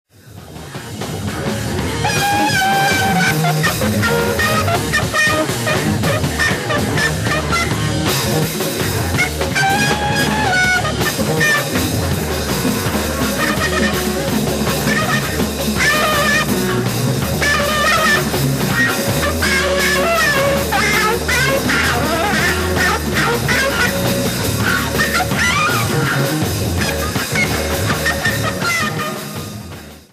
LIVE AT PHILHARMONIE, BERLIN 11/01/1973
SOUNDBAORD RECORDING
- "MONO EDITION" -